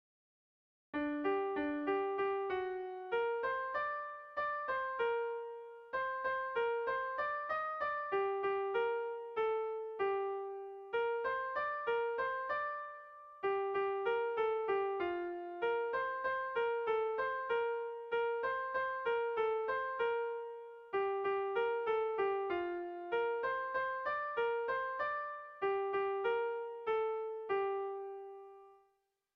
Bertso melodies - View details   To know more about this section
Tragikoa
ABD...